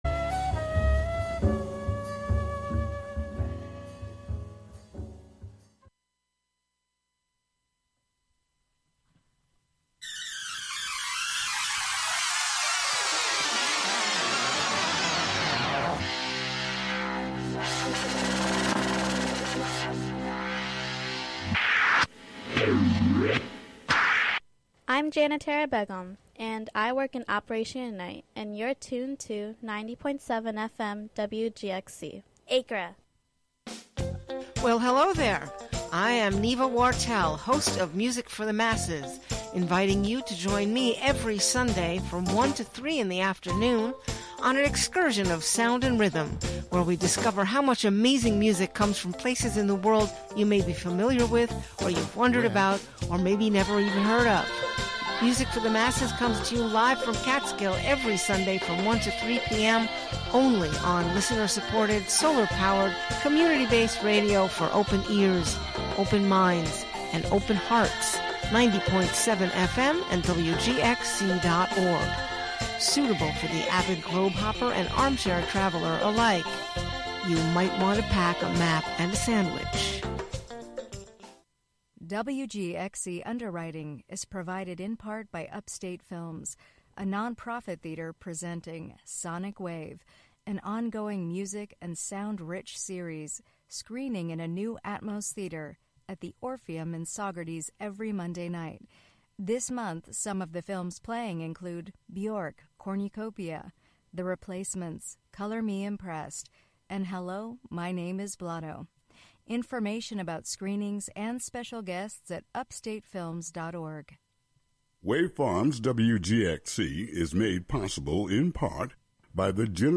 What started as a photography series then video series, "Uncontaminated Sound" is a conversation series for radio that follows comedians, musicians, fine artists, and actors with the primary objective to gain a particular insight into their creative processes which can only be fully unravelled by truly going behind the scenes.
These features offer listeners real, raw, and authentic conversations.